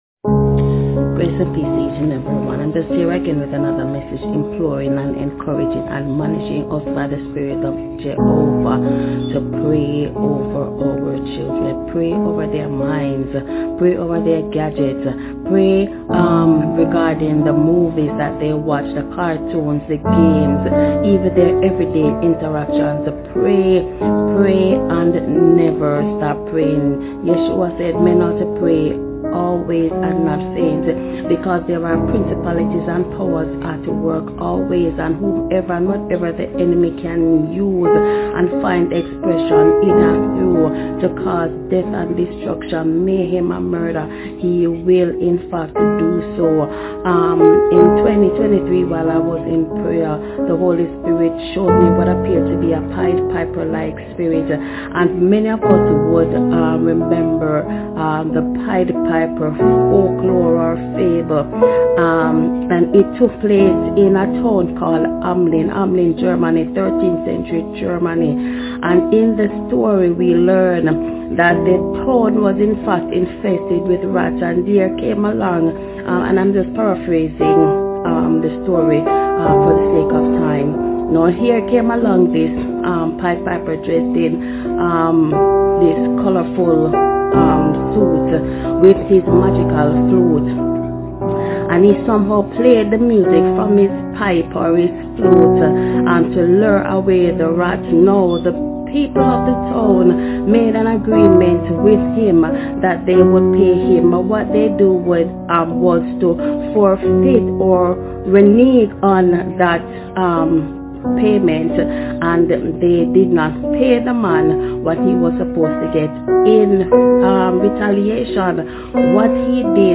I tried listening to the audio, and the music was too loud to really hear and understand what was being said.